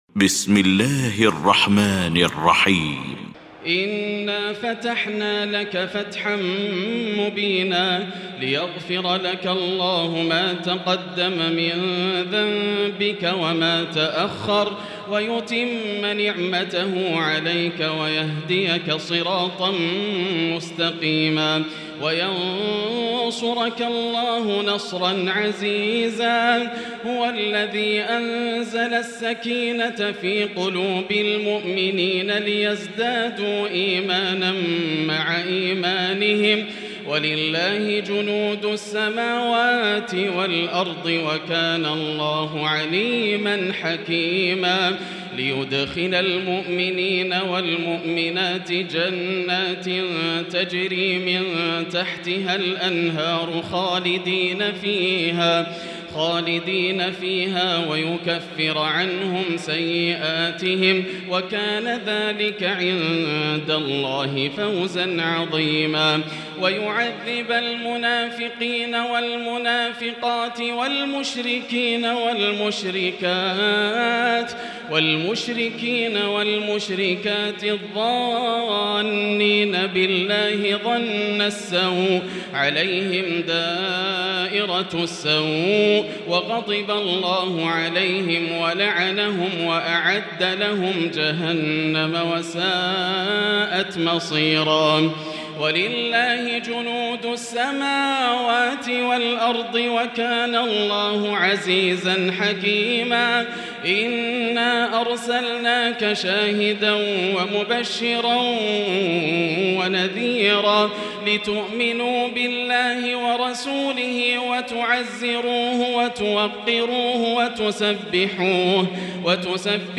المكان: المسجد الحرام الشيخ: فضيلة الشيخ ياسر الدوسري فضيلة الشيخ ياسر الدوسري الفتح